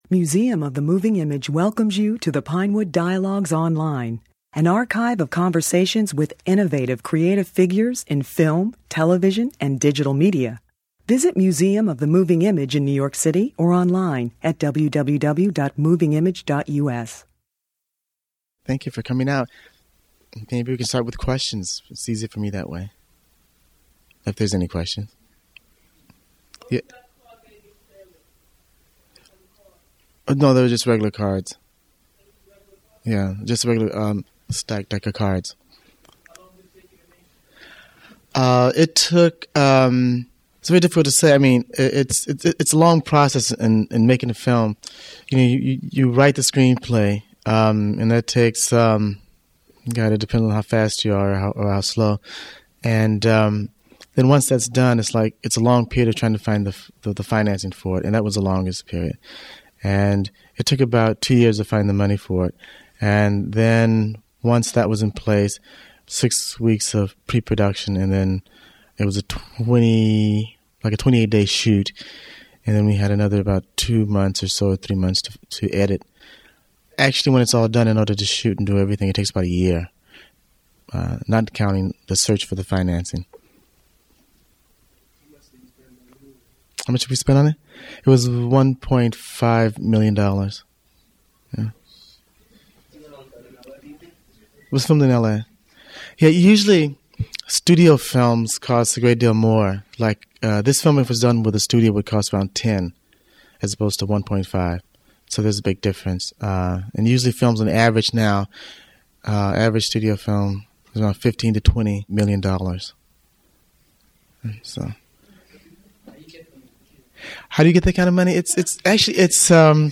During a retrospective of his films at the Museum of the Moving Image, he answered questions from the audience about To Sleep with Anger, his drama starring Danny Glover as a mysteriou